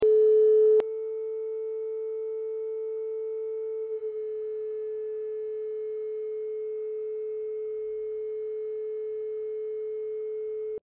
432Hz / 440Hz faraday waves sound effects free download
432Hz / 440Hz faraday waves rippling over water surface.